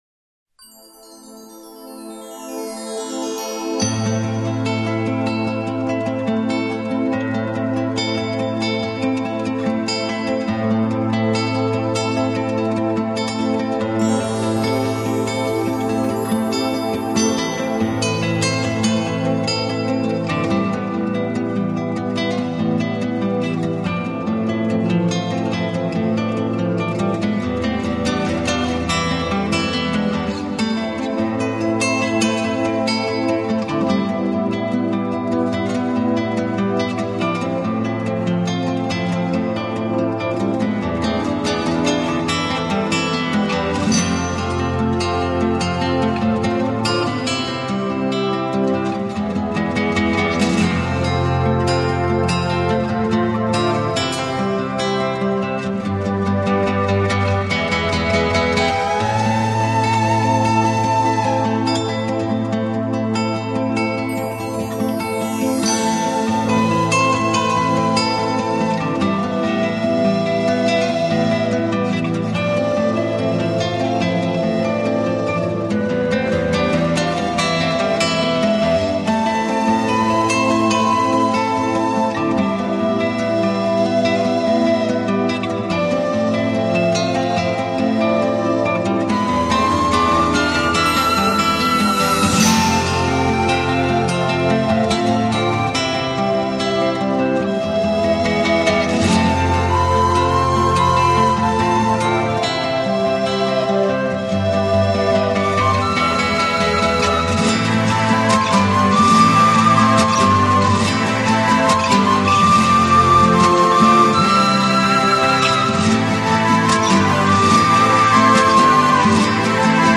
专辑歌手：纯音乐
本辑十五首平衡情绪的感性作品，新世界发烧EQ音乐。
旋律很有节奏性的美感，糅合了古典音乐和轻音乐的成分！